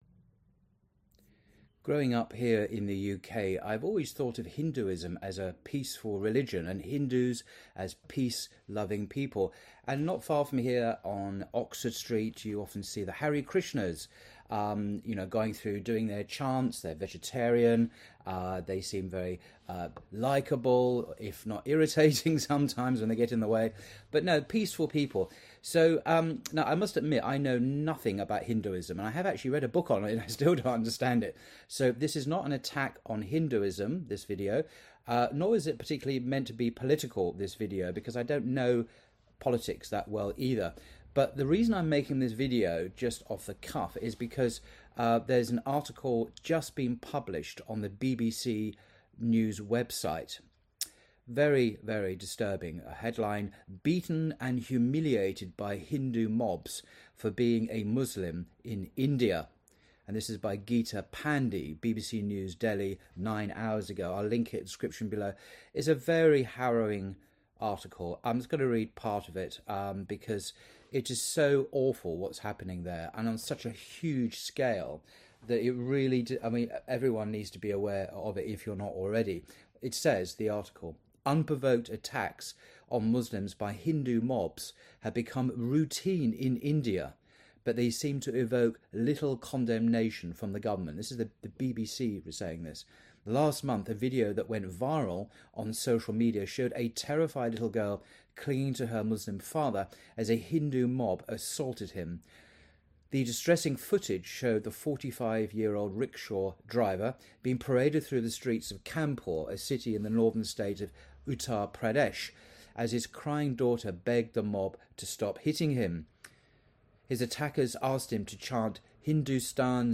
BBC news： 'Beaten and humiliated by Hindu mobs for being a Muslim in India'.mp3